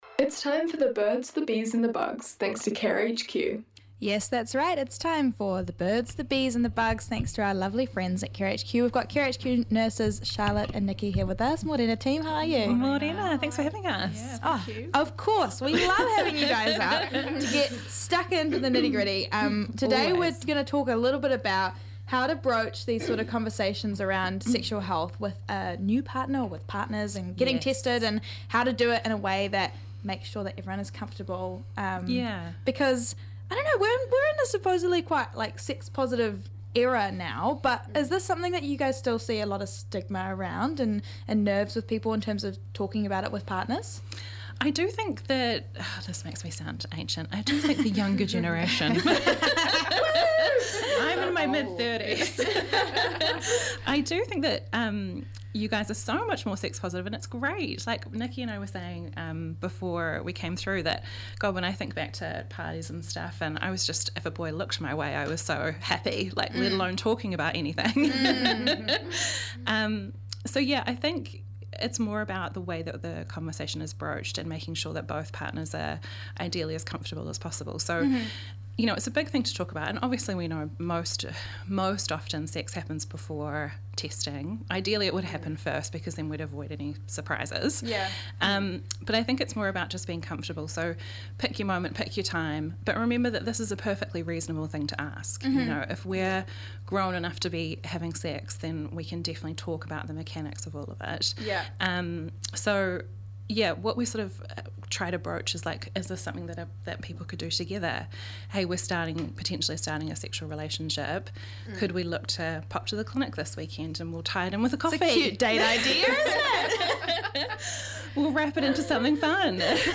Nurses explain all you need to know about sexual health and how to practice safe intimacy, thanks to Care HQ, an affordable online GP service making consultations accessible and stress free.